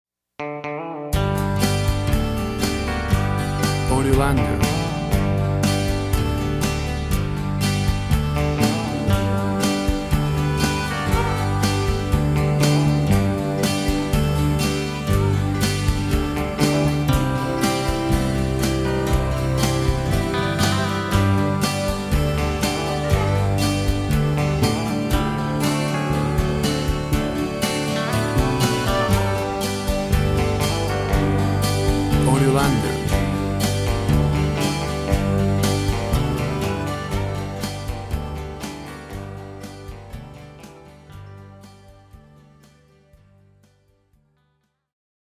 A short repetitive country/western instrumental track.
Tempo (BPM) 120